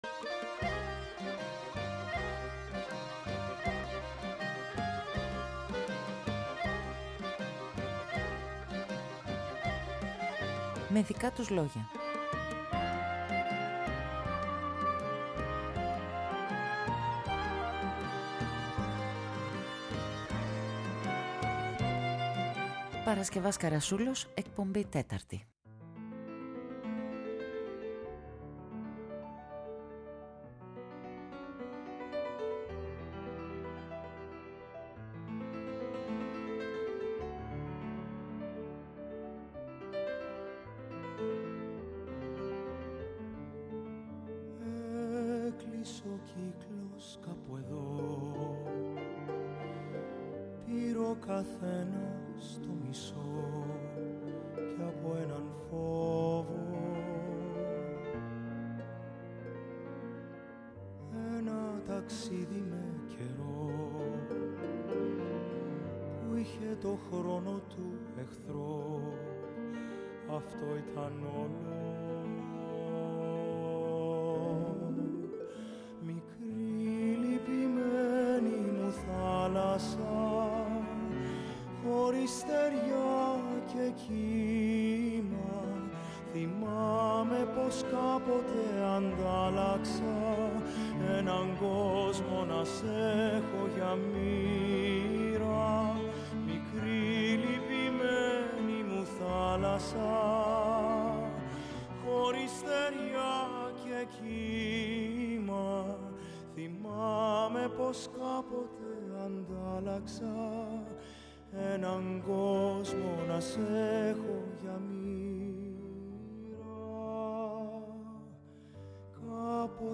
Στιγμιότυπα, ιστορίες, εικόνες, αναμνήσεις, σκέψεις. Πρόσωπα του πενταγράμμου αφηγούνται τη δική τους ιστορία…
ΔΕΥΤΕΡΟ ΠΡΟΓΡΑΜΜΑ Με Δικα τους Λογια Αφιερώματα Μουσική Συνεντεύξεις